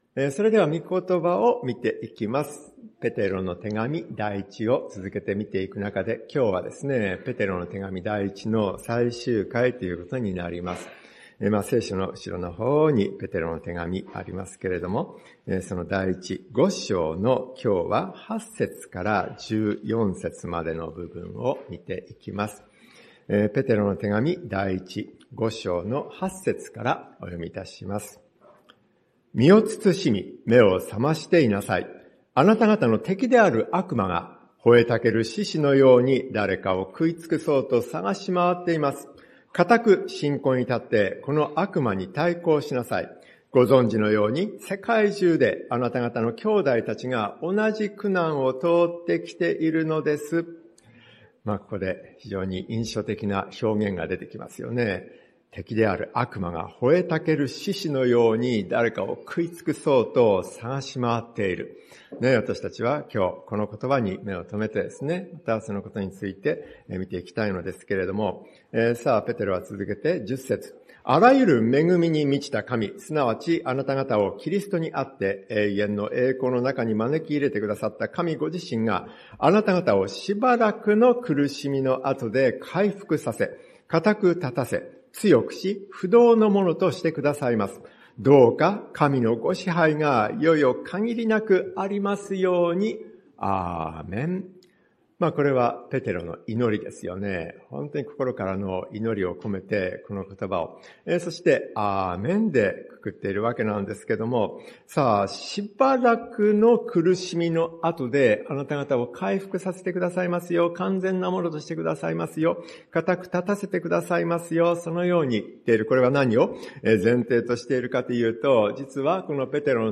吼えたける獅子 説教者